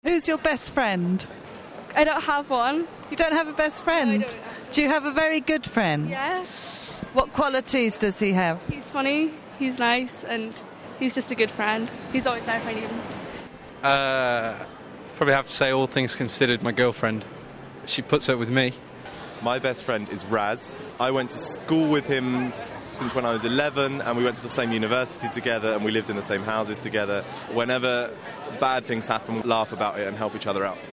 Every week we ask you a different question. Hear what people in London say, then join the conversation!